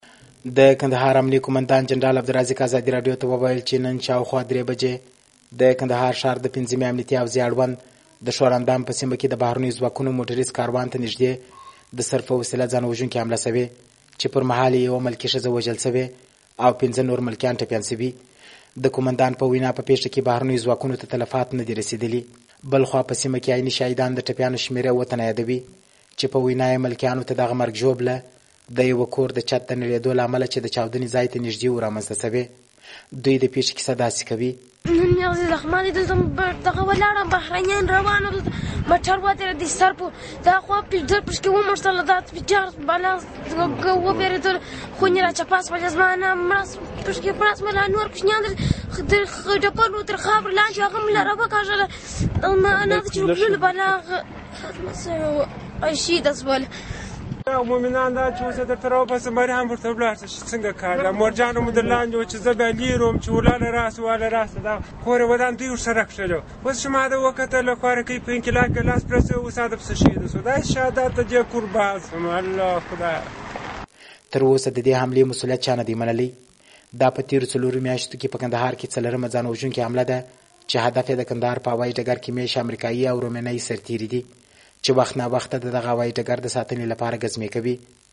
د پېښې له ځایه دا راپور برابر کړی دی.
د کندهار راپور